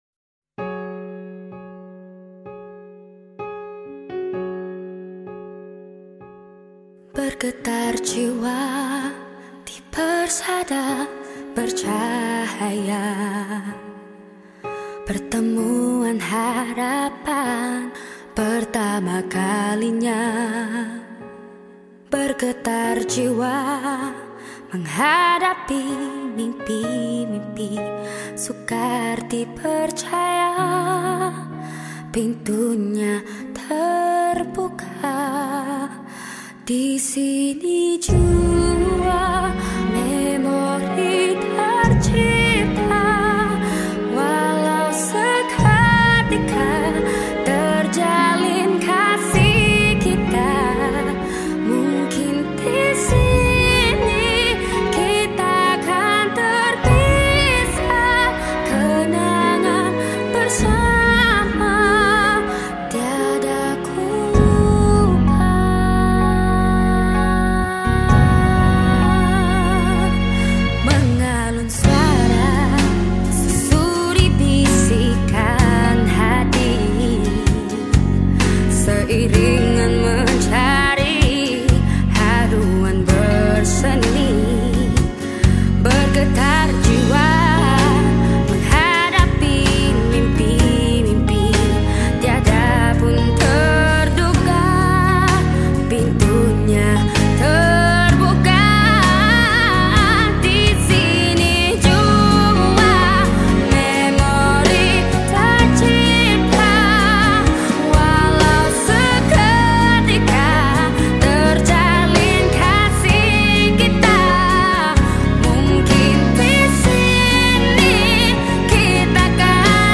Malay Songs